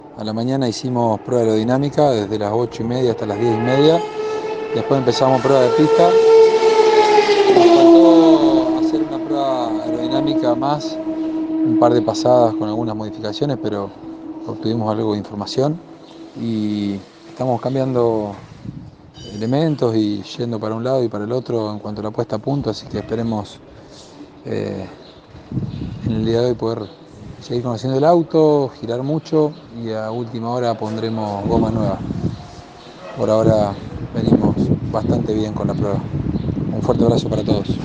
El piloto de Las Parejas pasó por los micrófonos de Pole Position y habló de como se viene desarrollando la prueba en el Autódromo de La Plata con el Torino del Rus Med Team.